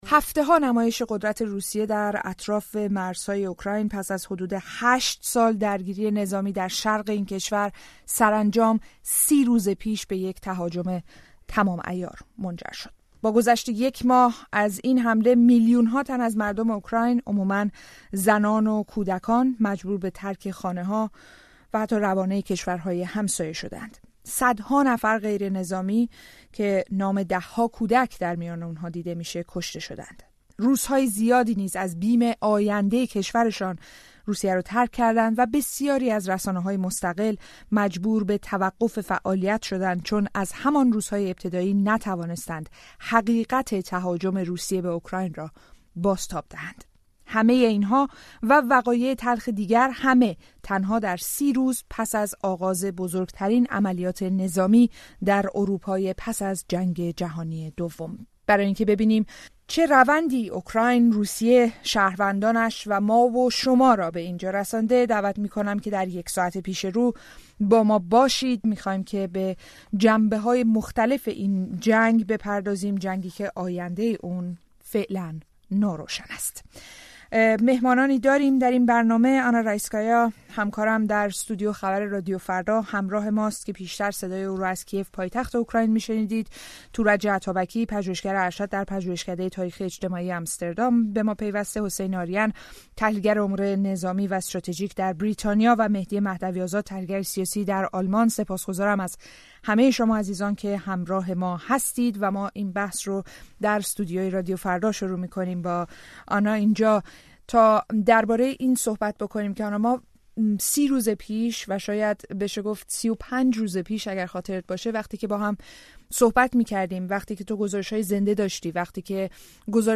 میزگردی ویژه